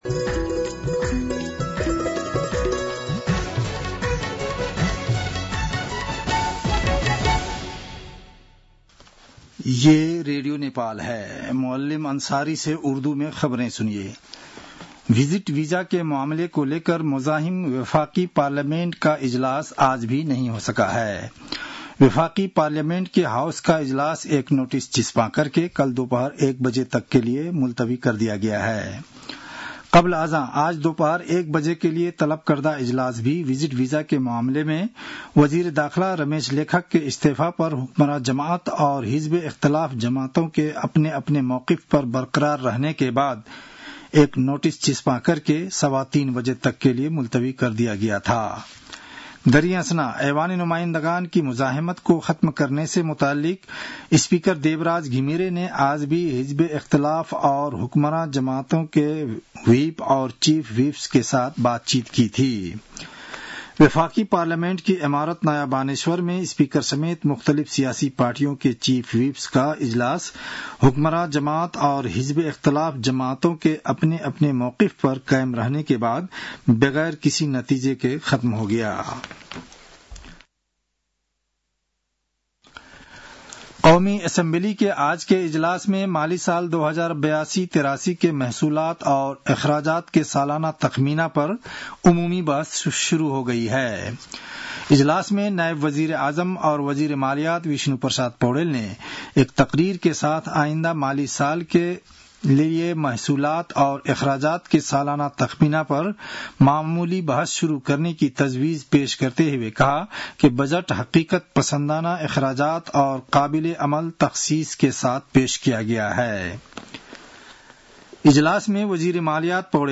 उर्दु भाषामा समाचार : २१ जेठ , २०८२